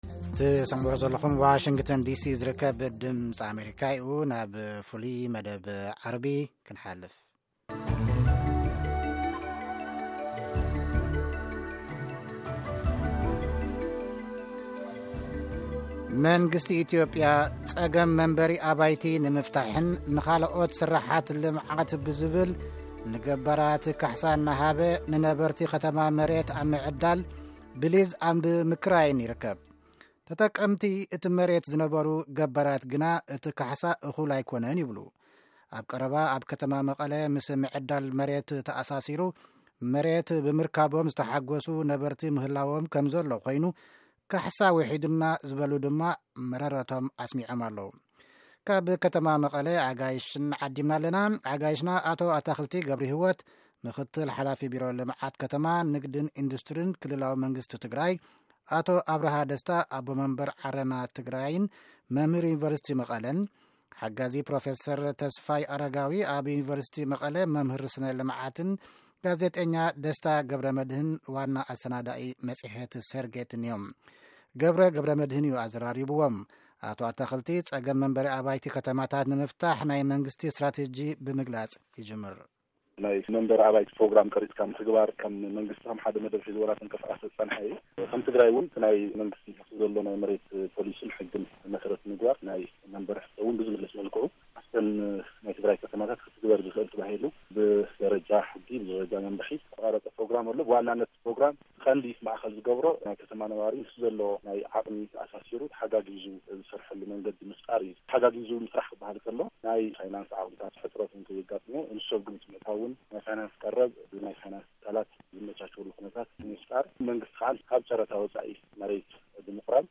ነቲ መሬት ዝጥቀምሉ ዝነበሩ ገባራት ግን እቲ ካሕሳ እኹል ኣይኮነን ይብሉ። ምስ ፖሊሲን ኣፈፃፅማን መሬት ከምኡ እውን ኣከፋፍላ ካሕሳ ብዝምልከት ንኣርባዕተ ኣጋይሽ ዓዲምና ኣዘራሪብና ኣለና።